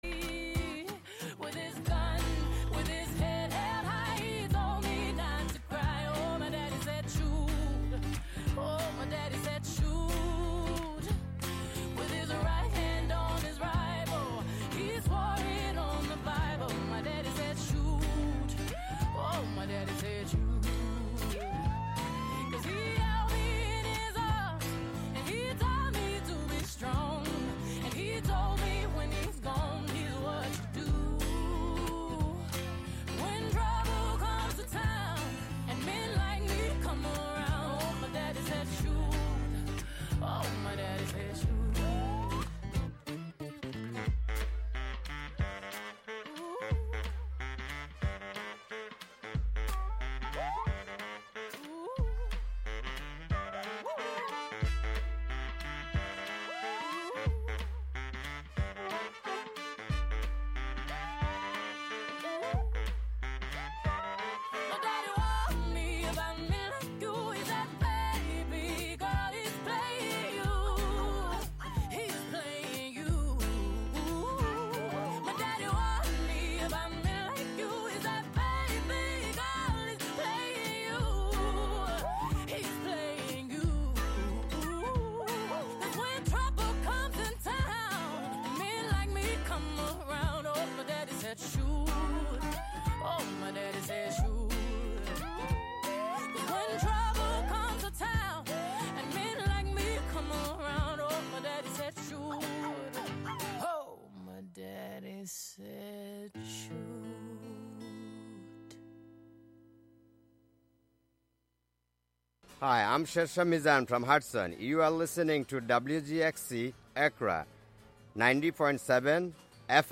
Through Censored, The Word Shop, and Our Town Our Truth, we dig into the topics that matter. Our container: Radiolab, an open, experimental, youth-led programming and recording space. Show includes local WGXC news at beginning, and midway through.